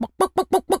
chicken_cluck_bwak_seq_08.wav